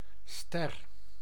Ääntäminen
IPA: /stɛr/